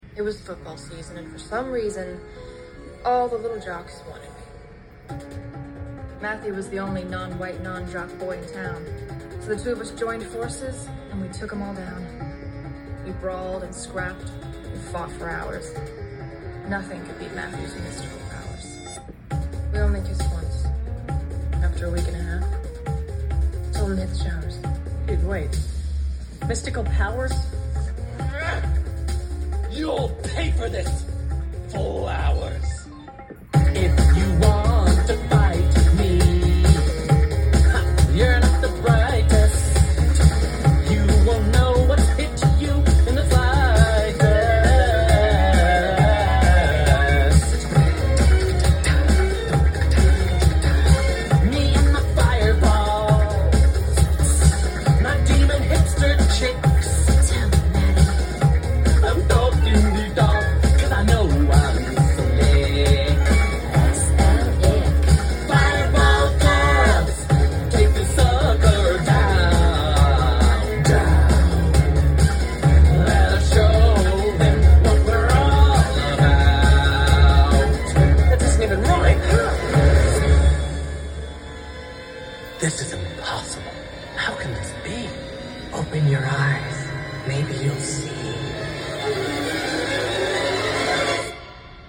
pressed on blue vinyl